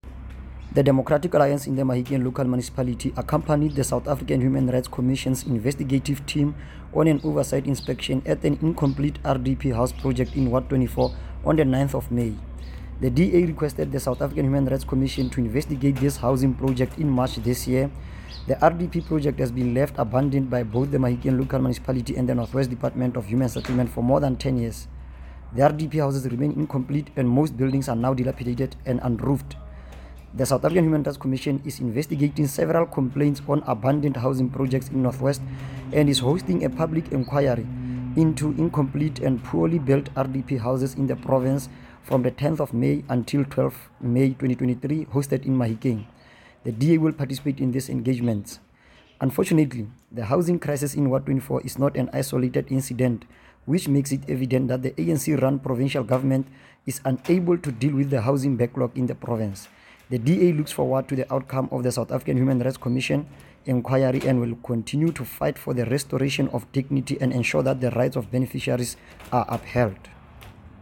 Note to Broadcasters: Please find linked soundbites in
Setswana by Cllr Neo Mabote.